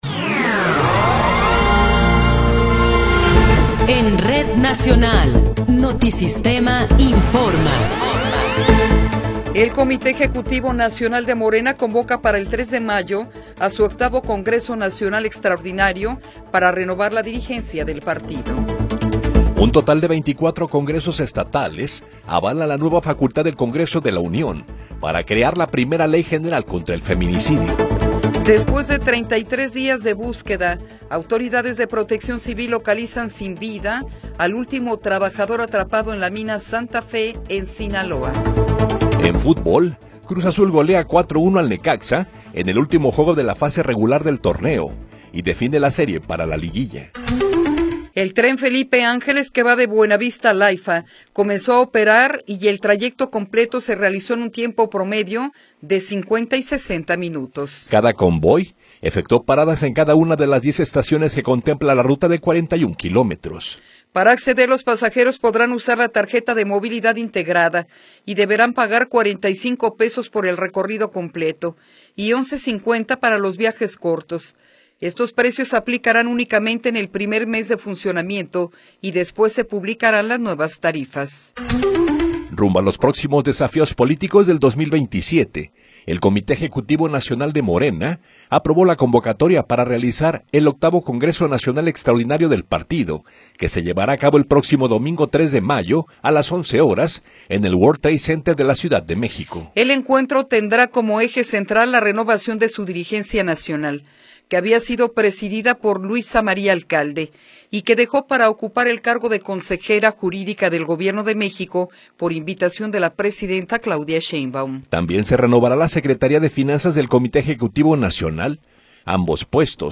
Noticiero 8 hrs. – 27 de Abril de 2026
Resumen informativo Notisistema, la mejor y más completa información cada hora en la hora.